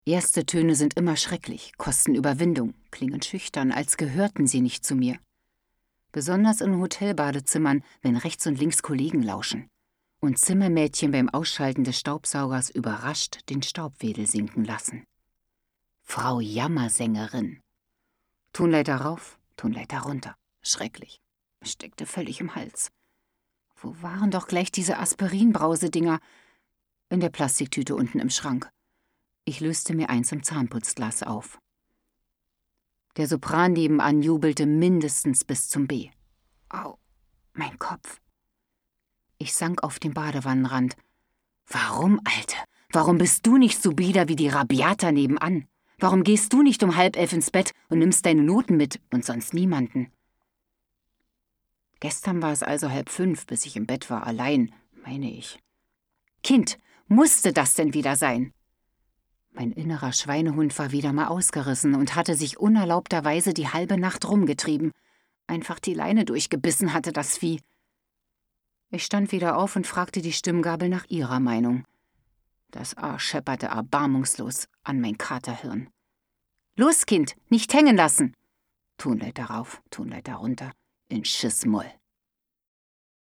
Dein Browser unterstüzt kein HTML5 Hörbuch Beispiel 1 Hörbuch Beispiel 2 Verrückt Geworden